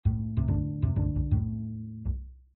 直立爵士低音提琴06
描述：爵士直立式贝司的变体01
Tag: 爵士乐